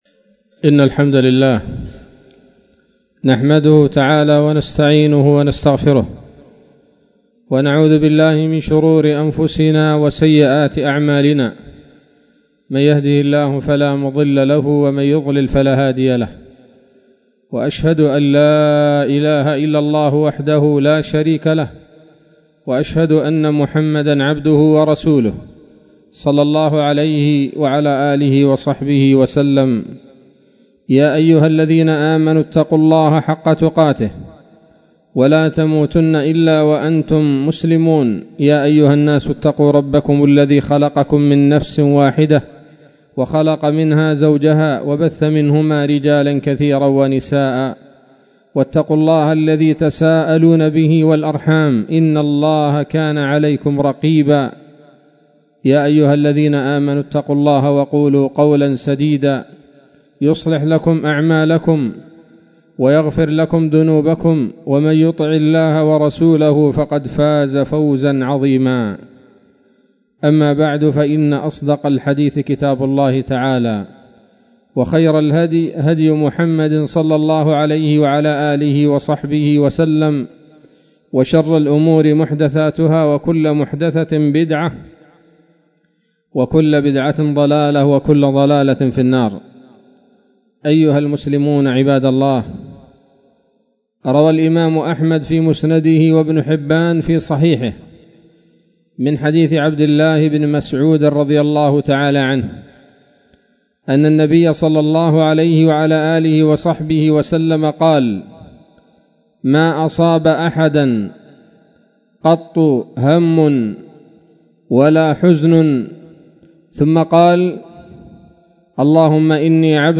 خطبة جمعة بعنوان: (( دعاء الهم )) 16 من شهر ربيع الآخر 1441 هـ